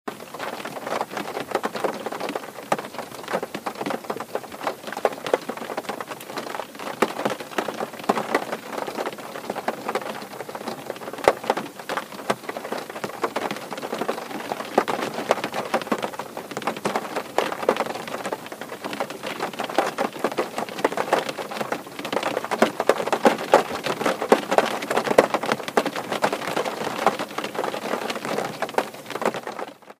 rain-in-car-ringtone_14245.mp3